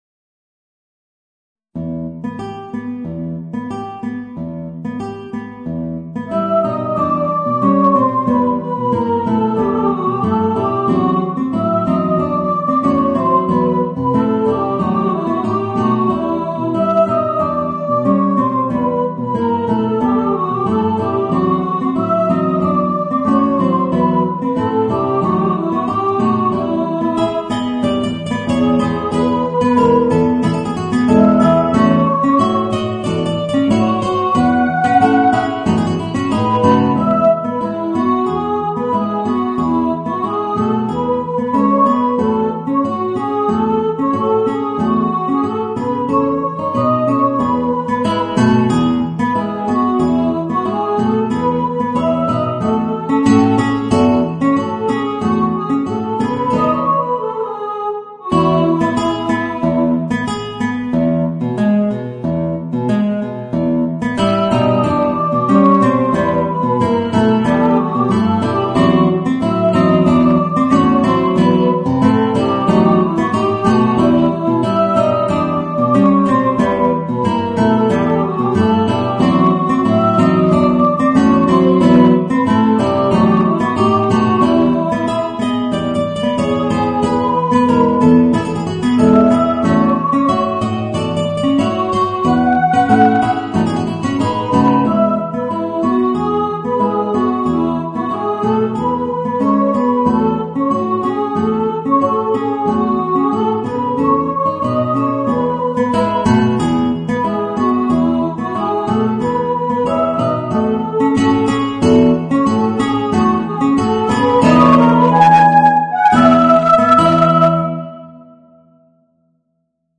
Voicing: Guitar and Soprano